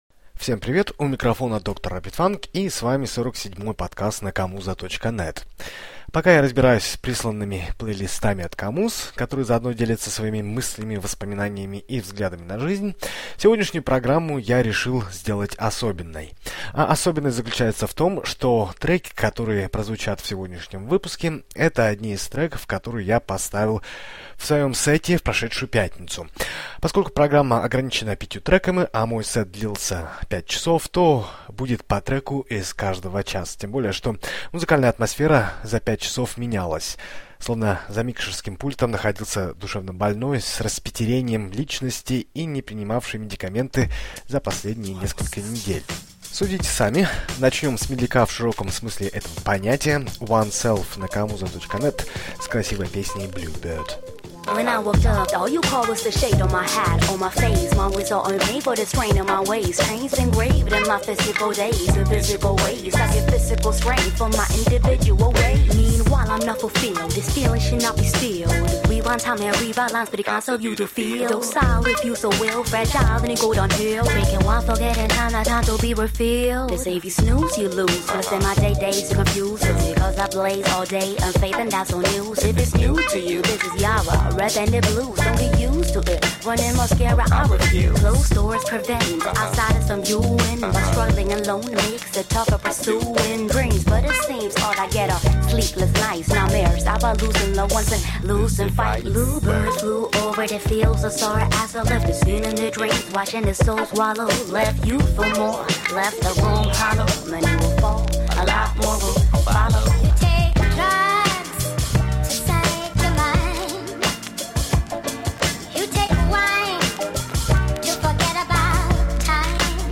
Горяченькое прямо из собственного живого сета.